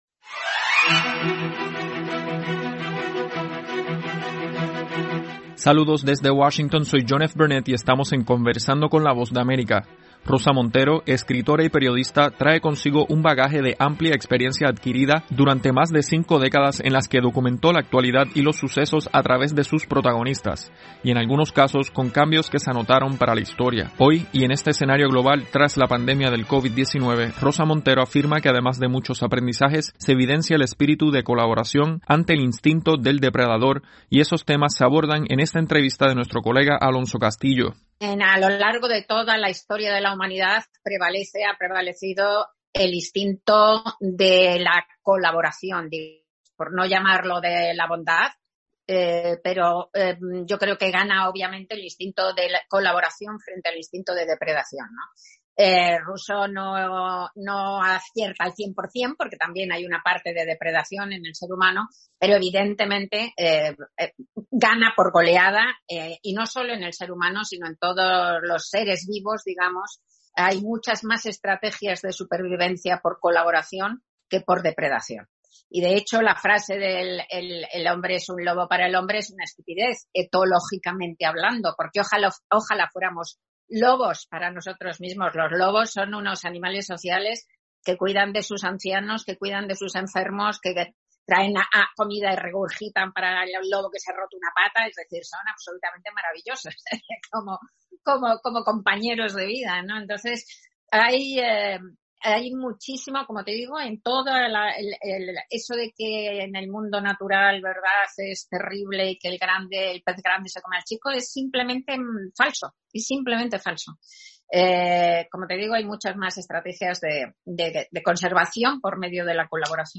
Conversamos con la periodista y escritora Rosa Montero hablando sobre la pandemia y el papel fundamental de la mujer en la gestión de la crisis.